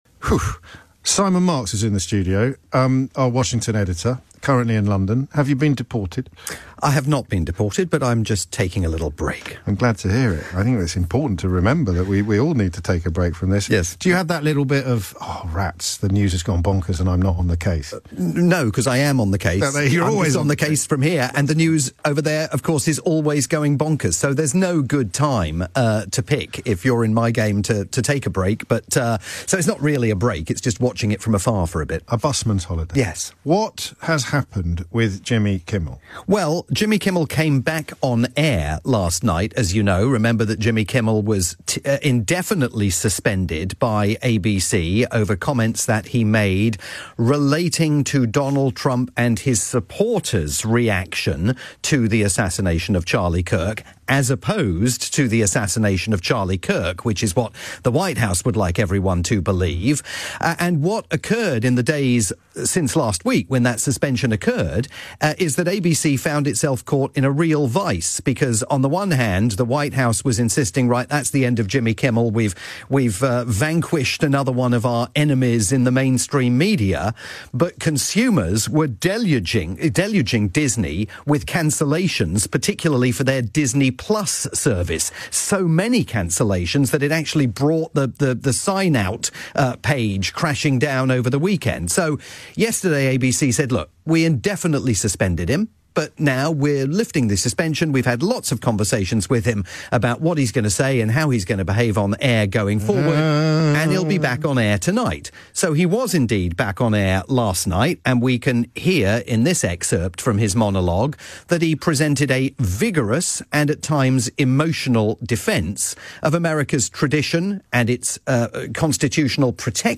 live update for James O'Brien's morning programme on the UK's LBC.